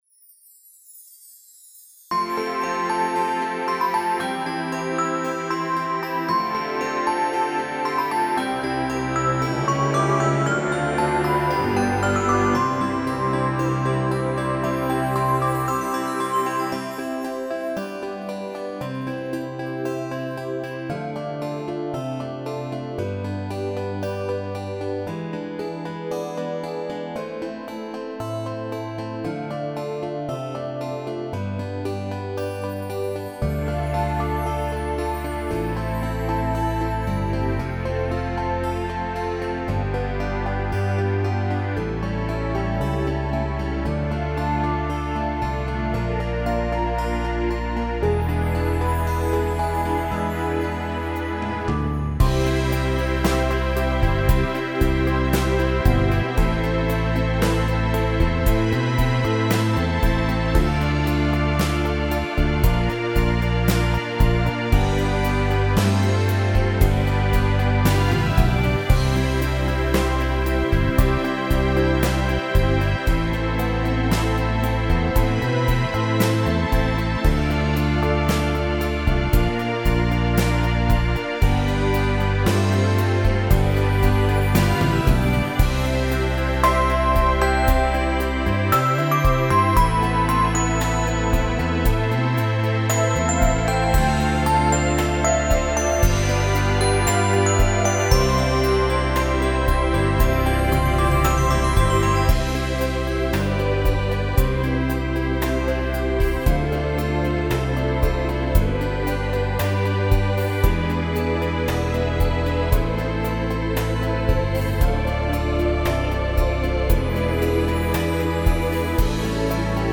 Исполняет автор